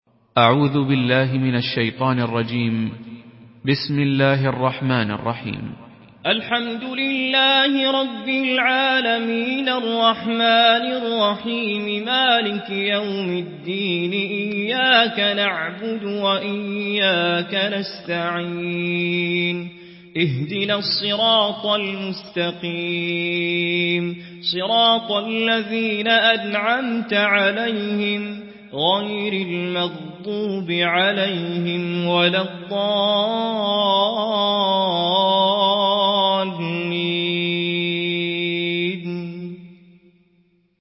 سورة الفاتحة MP3 بصوت توفيق الصايغ برواية حفص
مرتل حفص عن عاصم